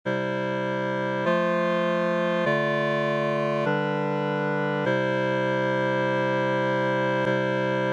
I-IV-ii-V64-I Corrected V
This also means that in this example, the bass would have to make a small adjustment in pitch upward between those two Ds.
i-iv-ii-v64-i-corrected-v.mp3